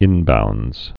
(ĭnboundz)